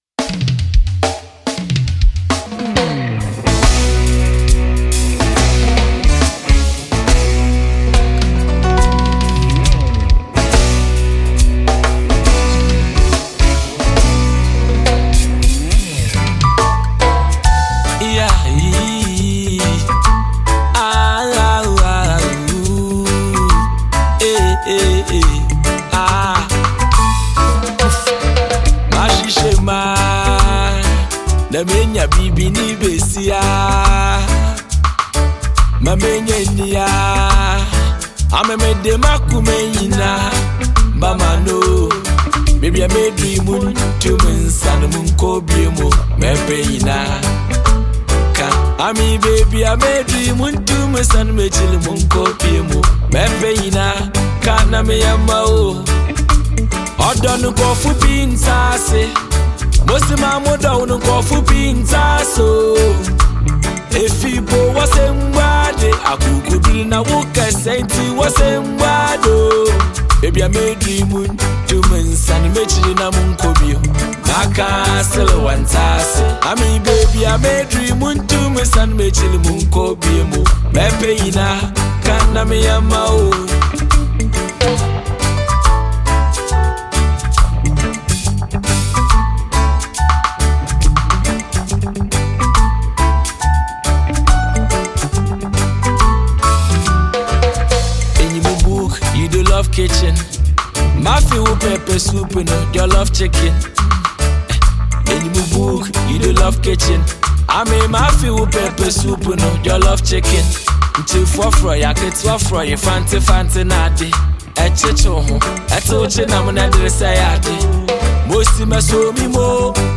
Highlife
love song